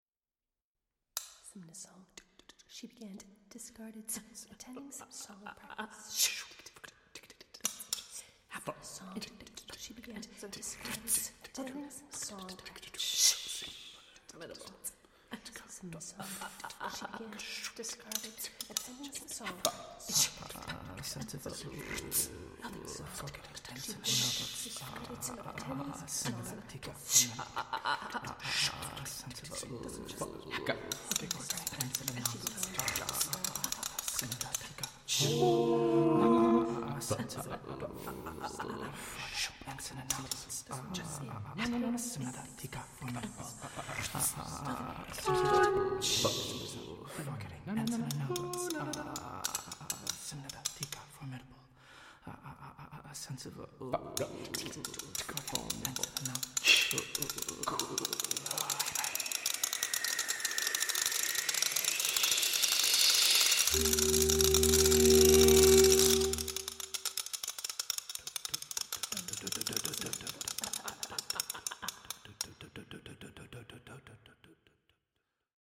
Recording location: Oktaven Audio, Mt. Vernon, NY